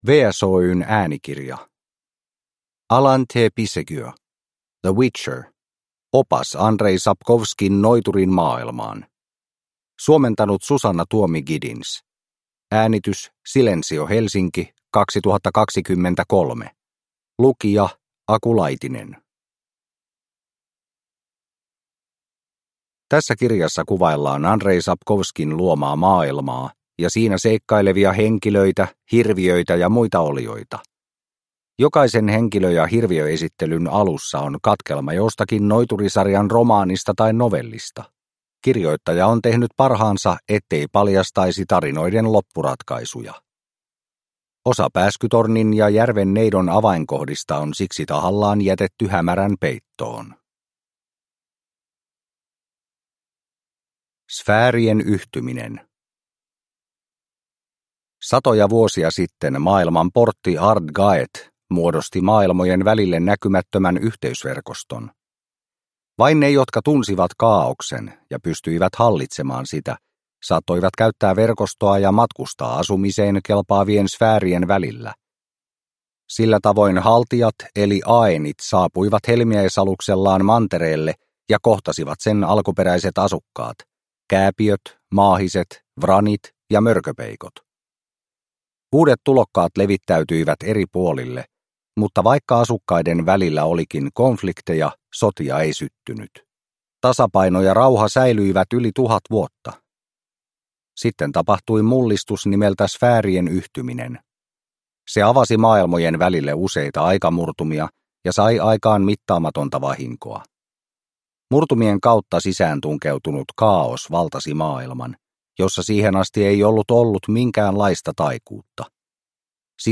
The Witcher – Opas Andrzej Sapkowskin Noiturin maailmaan – Ljudbok – Laddas ner